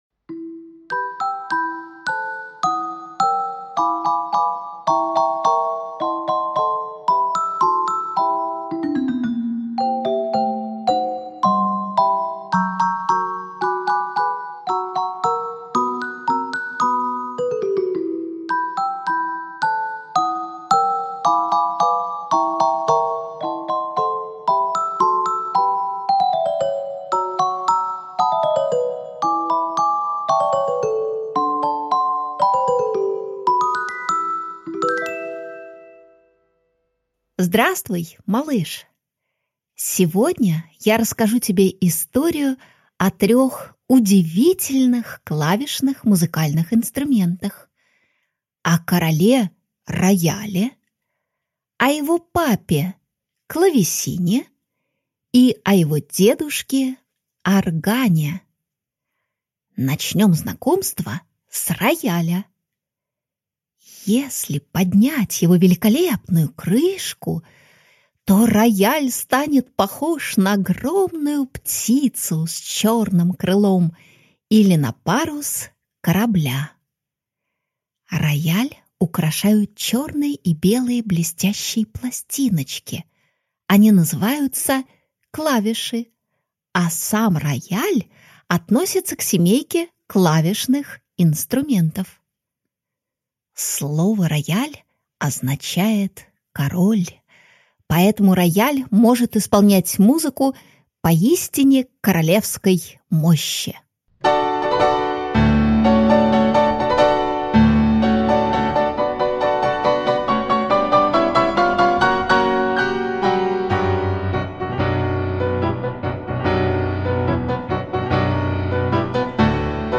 Аудиокнига Орган, клавесин, рояль и их волшебные истории | Библиотека аудиокниг